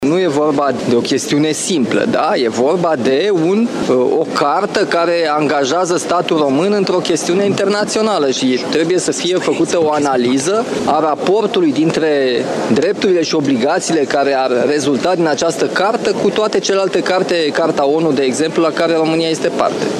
Reuniune extraordinară a Consiliului European la Bruxelles: președintele Nicușor Dan spune că Europa și România au nevoie de relația transatlantică și că este foarte bine că, în urma dialogului, tensiunile s-au diminuat.